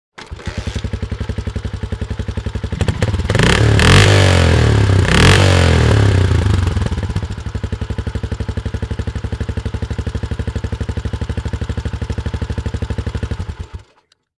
دانلود آهنگ استارت یا هندل موتور و گاز دادن 1 از افکت صوتی حمل و نقل
جلوه های صوتی
دانلود صدای استارت یا هندل موتور و گاز دادن 1 از ساعد نیوز با لینک مستقیم و کیفیت بالا